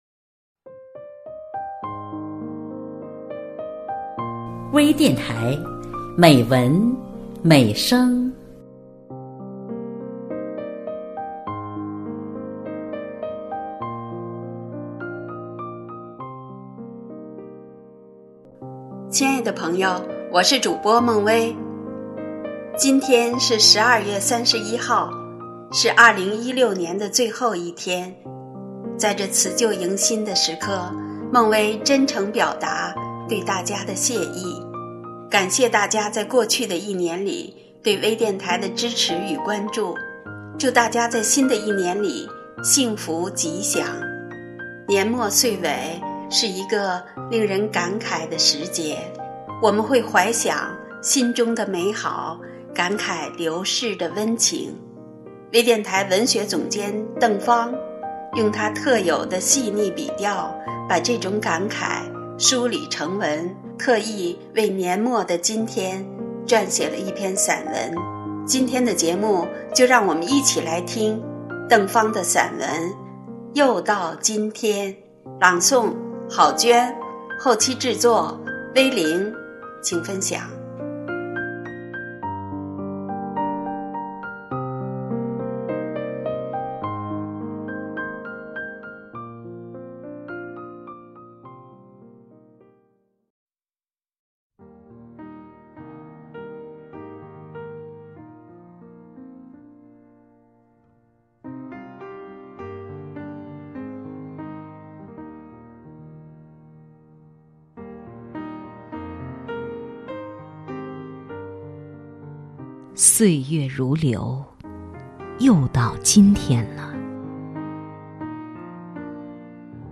专业诵读